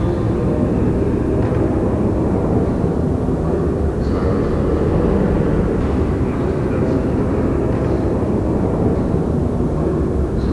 trainstation_ambient.wav